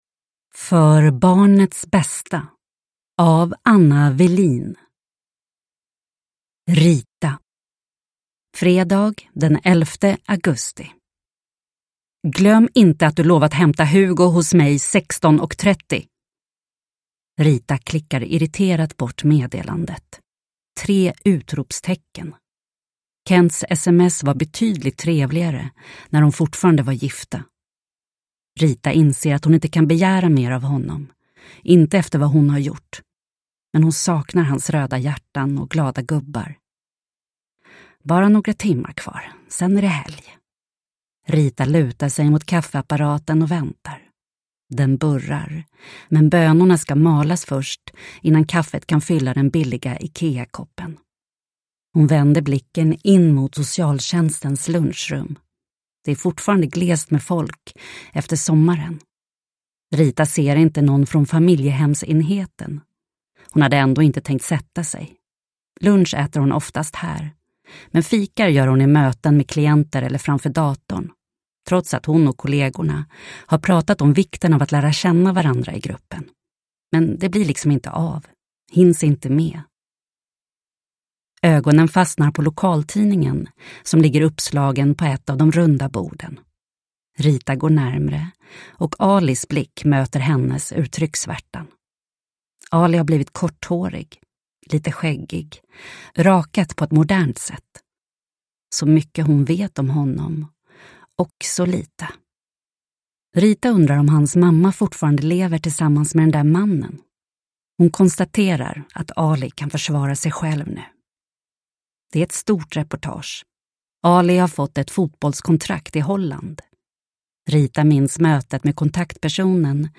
För barnets bästa – Ljudbok – Laddas ner